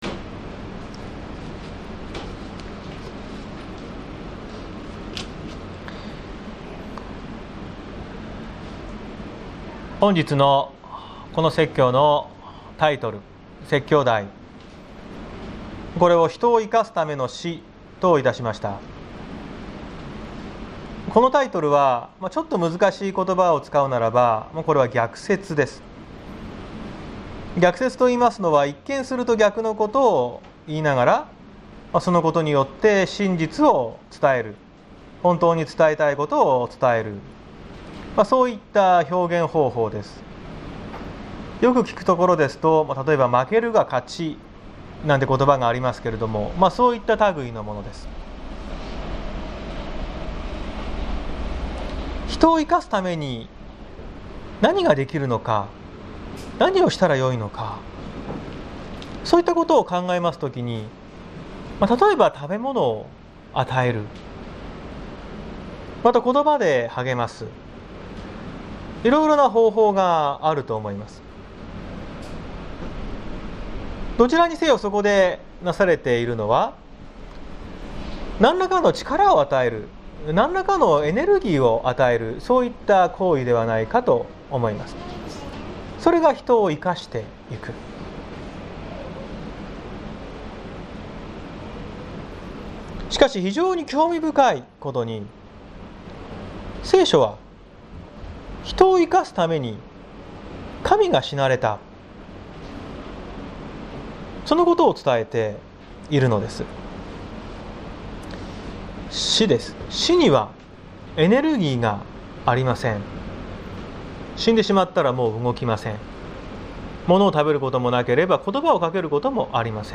2021年08月22日朝の礼拝「人を生かすための死」綱島教会
説教アーカイブ。